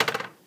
walkman_open empty.aiff